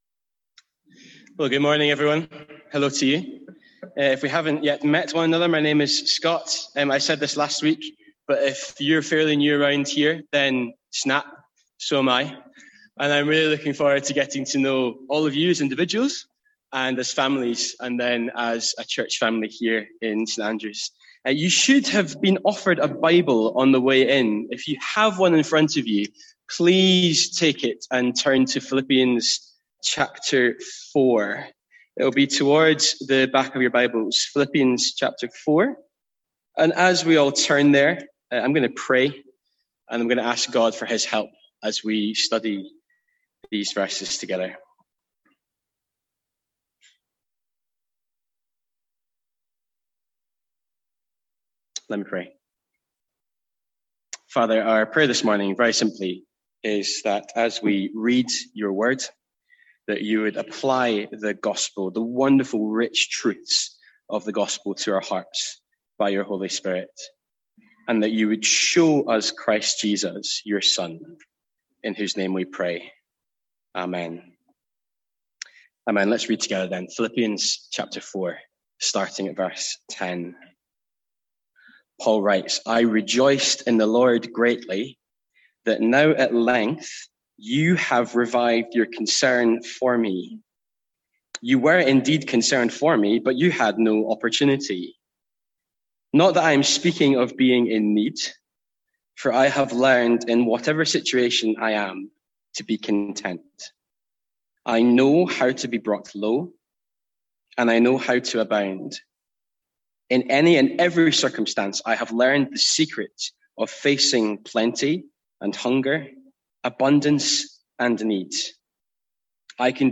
Sermons | St Andrews Free Church
From our morning series in Philippians